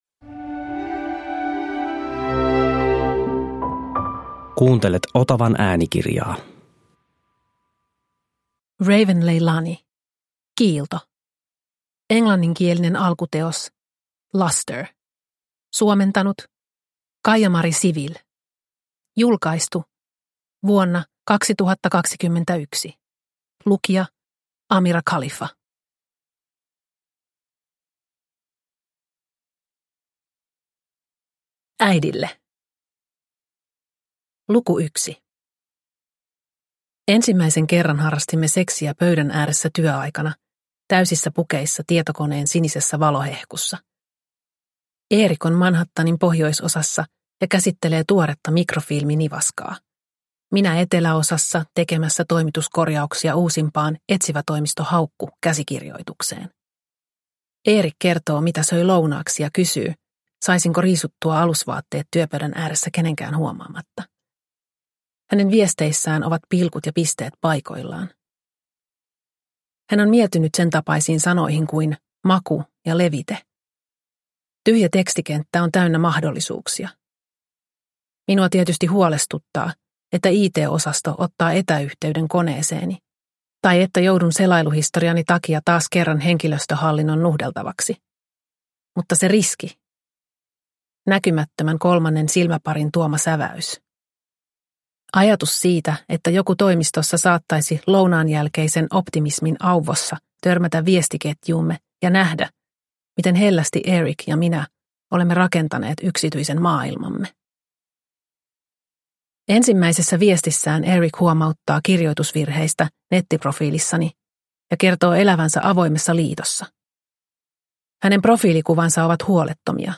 Kiilto – Ljudbok – Laddas ner